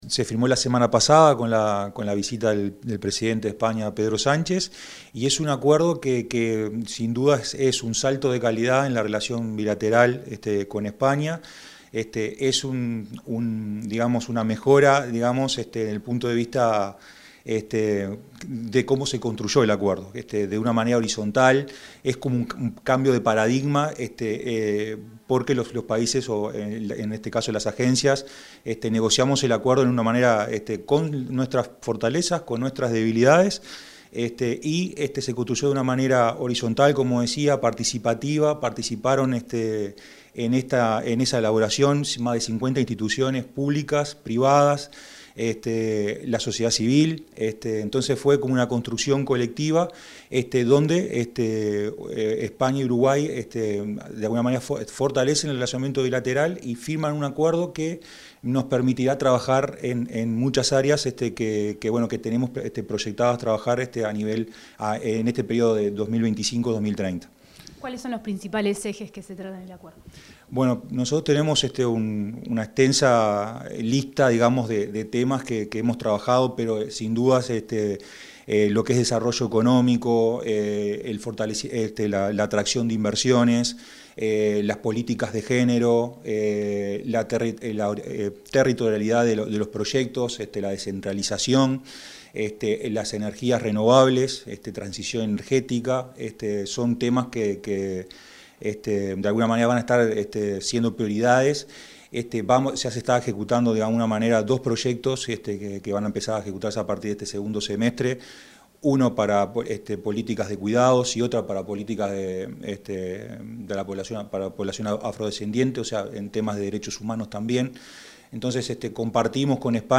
Declaraciones del director ejecutivo de AUCI, Martín Clavijo
Declaraciones del director ejecutivo de AUCI, Martín Clavijo 30/07/2025 Compartir Facebook X Copiar enlace WhatsApp LinkedIn Tras la firma de un acuerdo estratégico de cooperación entre los gobiernos de Uruguay y España, el director ejecutivo de la Agencia Uruguaya de Cooperación Internacional (AUCI), Martín Clavijo, efectuó declaraciones sobre los principales ejes estratégicos del compromiso.